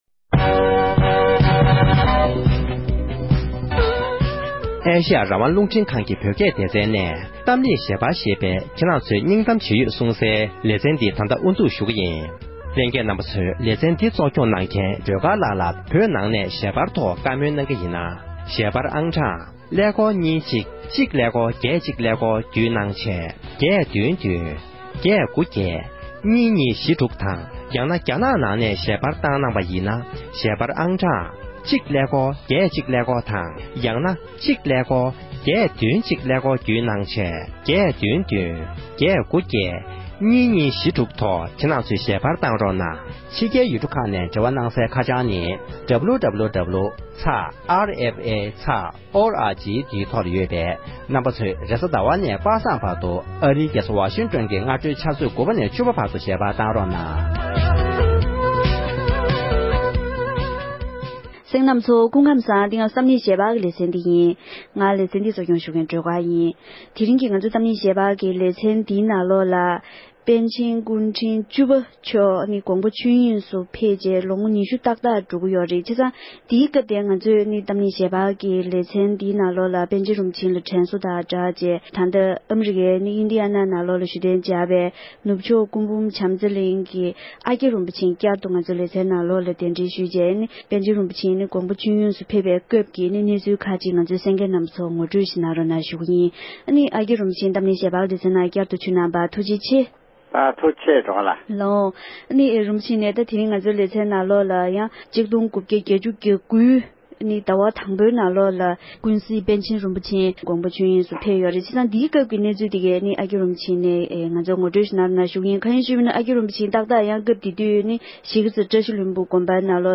པཎ་ཆེན་རིན་པོ་ཆེ་སྐུ་གོང་མའི་རྗེས་དྲན་གྱི་བགྲོ་གླེང༌།